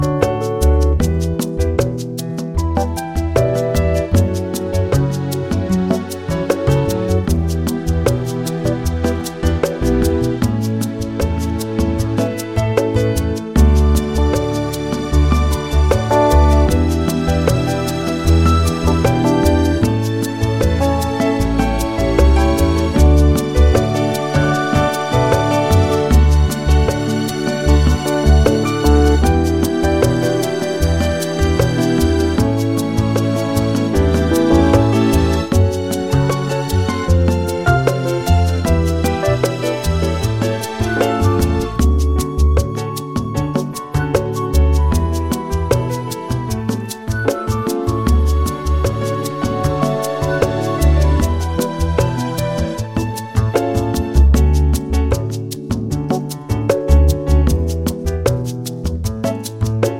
Female Key of D